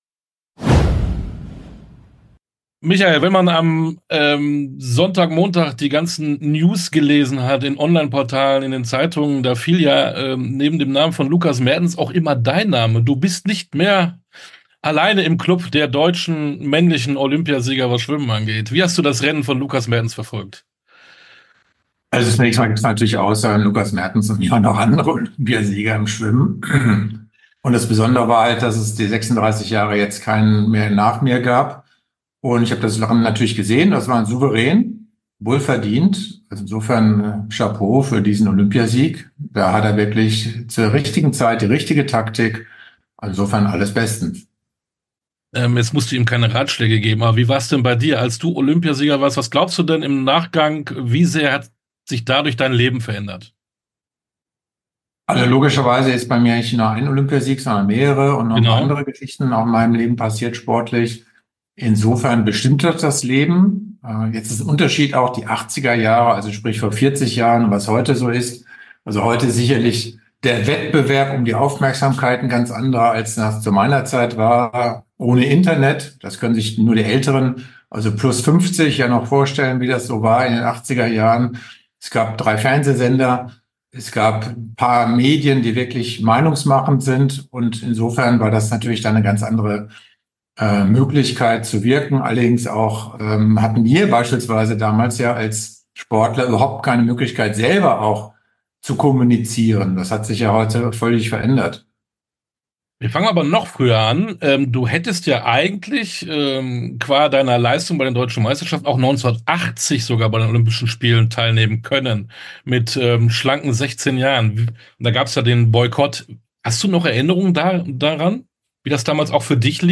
Sportstunde - Interview komplett Michael Groß Schwimmer dreimaliger Olympiasieger 1 ~ Sportstunde - Interviews in voller Länge Podcast